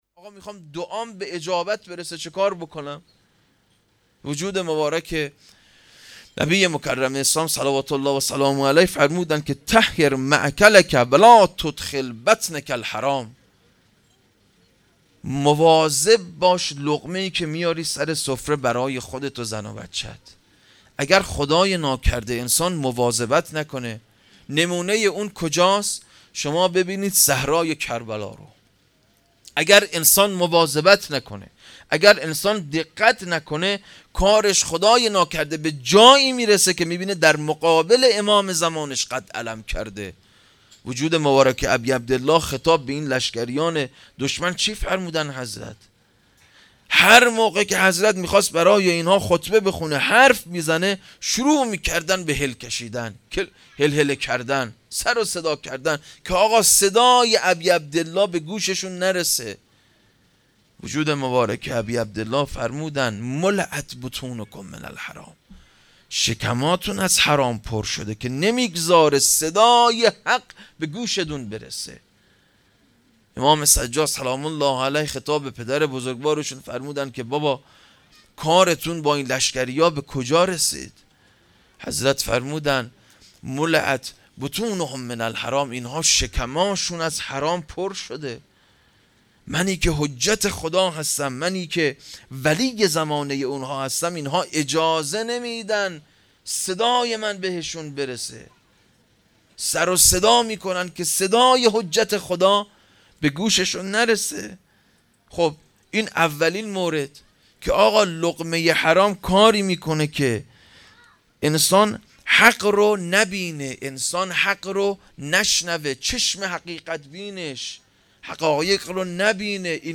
هیئت رایة الزهرا(سلام الله علیها)یزد